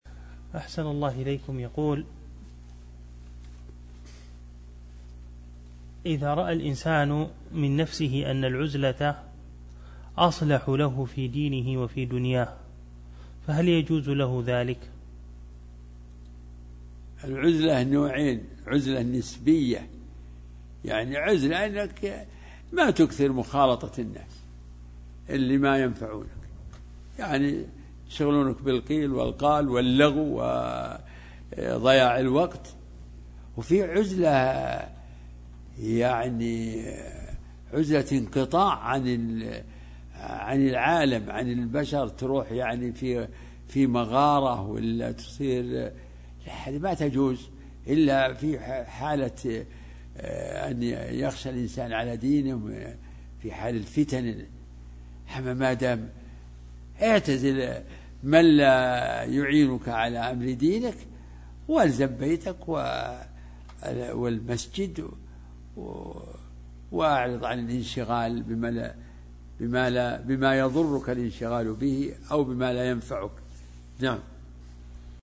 فتاوى الدروس